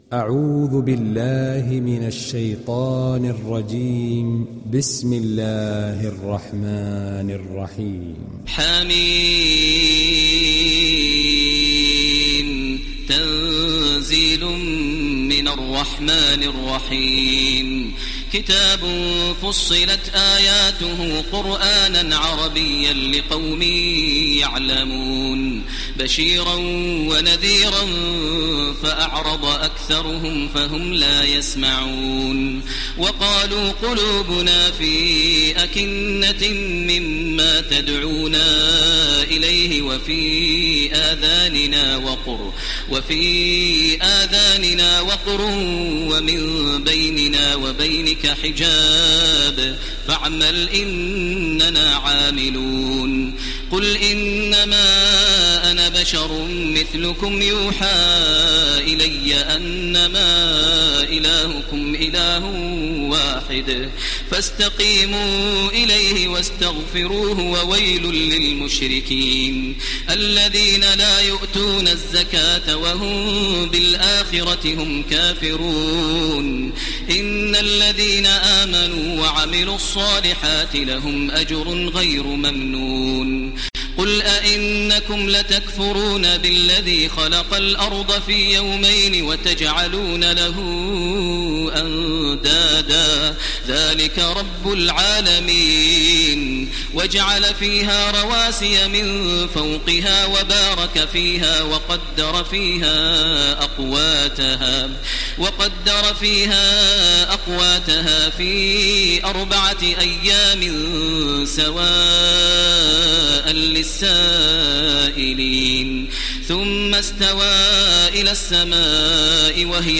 Télécharger Sourate Fussilat Taraweeh Makkah 1430